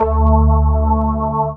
54_16_organ-A.wav